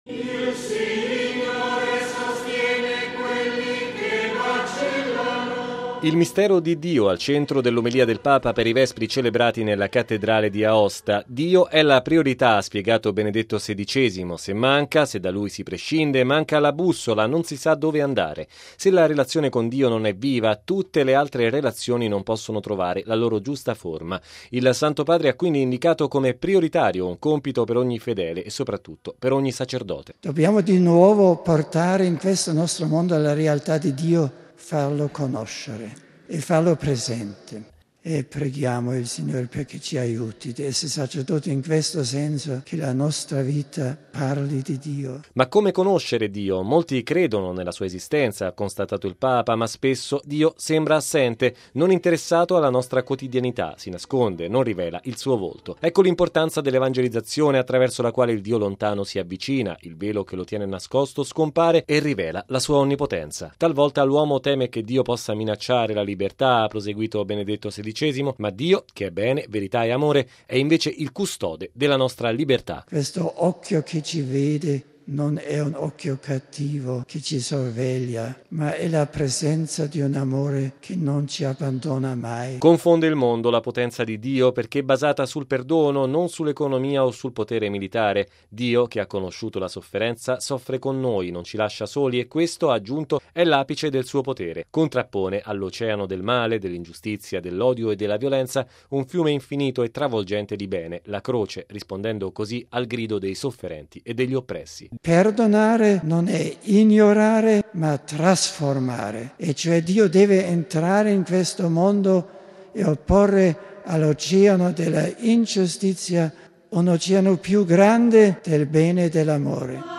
Così il Papa ieri pomeriggio durante la celebrazione dei Vespri nella Cattedrale di Aosta. Il Potere di Dio - ha aggiunto - non è nell’economia, o nella forza militare, ma nel perdono.